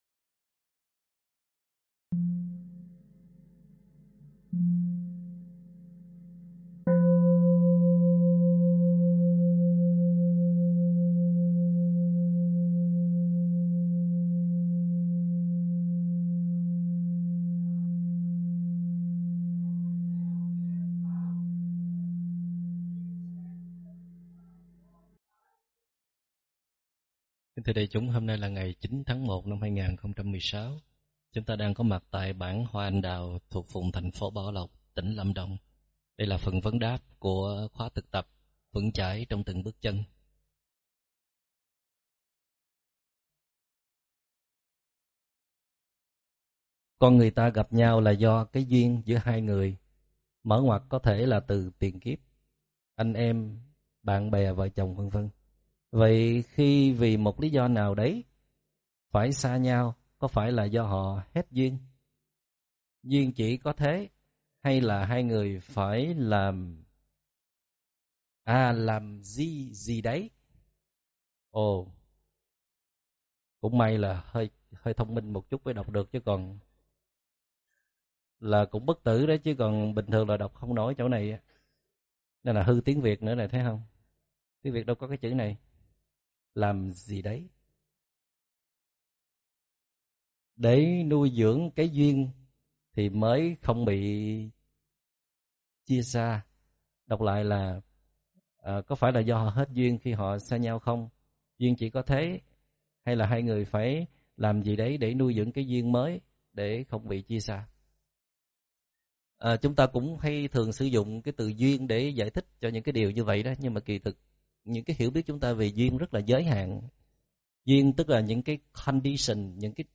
Thích Minh Niệm giảng ngày 9 tháng 1 năm 2016 Mp3 Thuyết Pháp Thích Minh Niệm 2016 Thiền hiểu biết Thuyết pháp Thích Minh Niệm